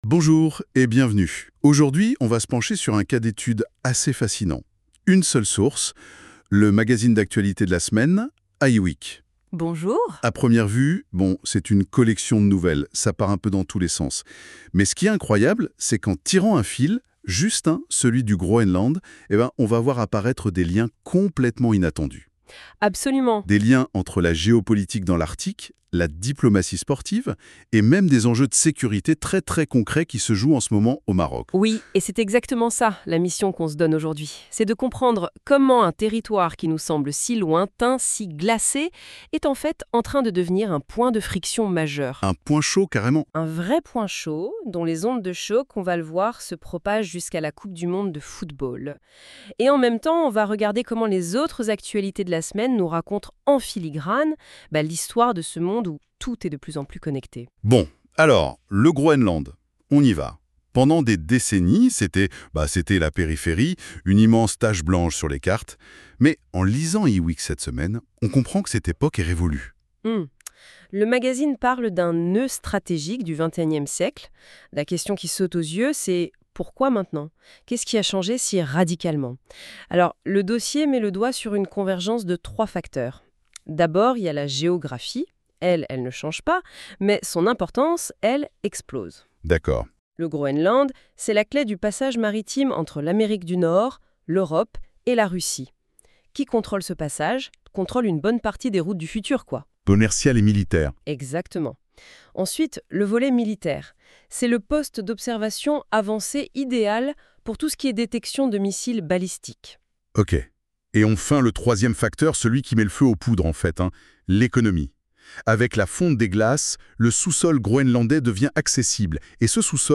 Podcast - débat I-WEEK 115 du 24 janvier 2026.mp3 (13.22 Mo)